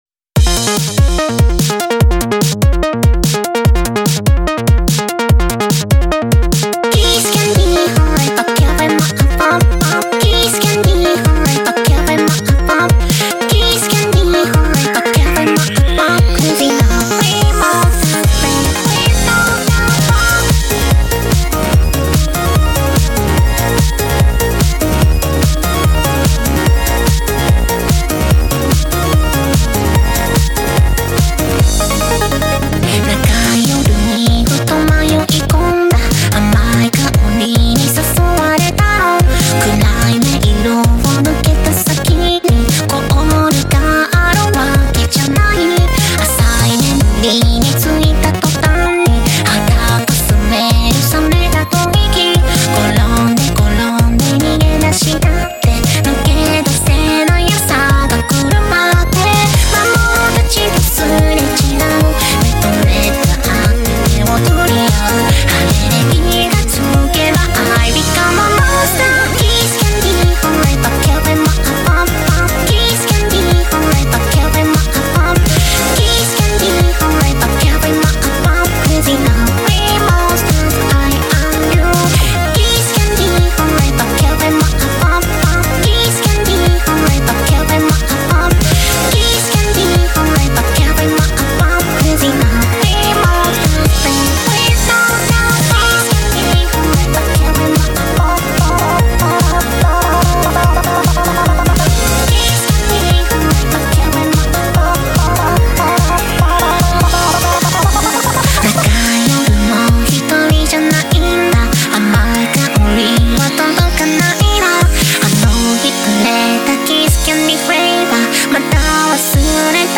BPM146
Genre: TECHNO POP.
A pretty poppy and sweet song.